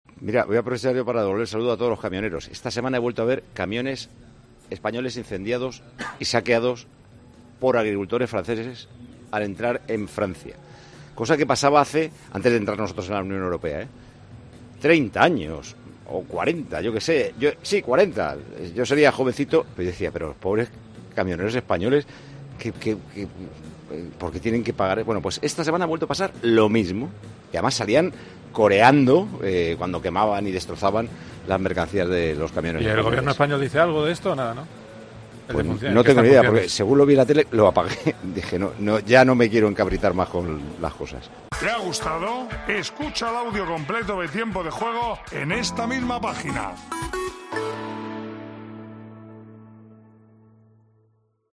Durante el programa de este domingo de Tiempo de Juego, el director y presentador Paco González quiso mandar un mensaje de apoyo a todos ellos.